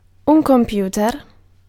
Ääntäminen
IPA: /ɔʁ.di.na.tœʁ/